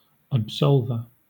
Ääntäminen
IPA : /æbˈzɑl.vɚ/